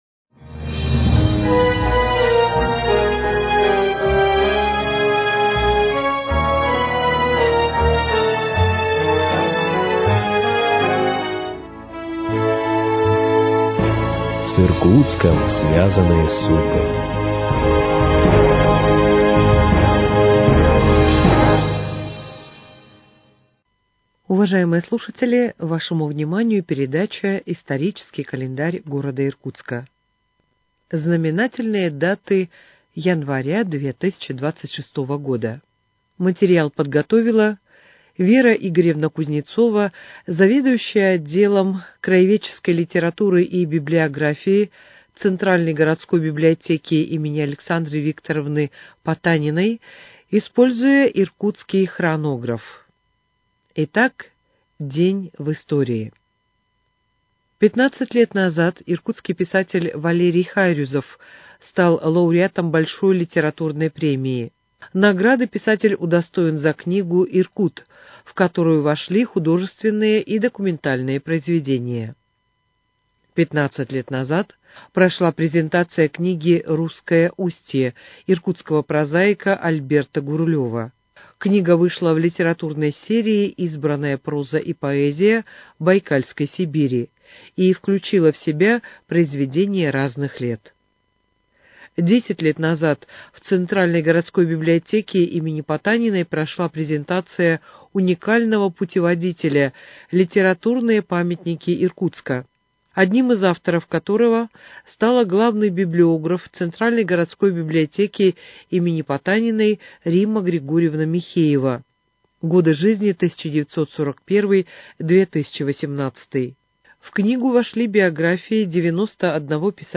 Знаменательные даты января 2026 года для нашего города. Текст читает ведущая